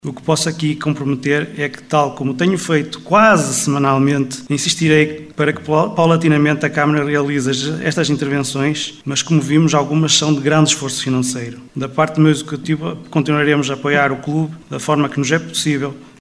Sobre o estado de conservação do Estádio Ilídio Couto também o presidente da Junta de Freguesia de Lanhelas, Adolfo Marrocos, fez questão de intervir para dizer que este é um assunto que preocupa a junta de freguesia e que tem vindo a ser debatido constantemente com a câmara e com o clube.